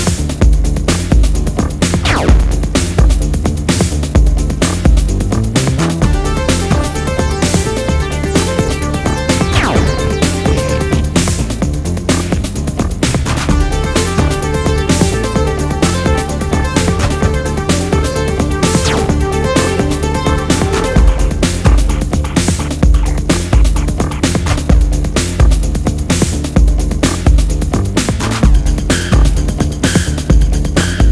From hypnotic rhythms to enveloping melodies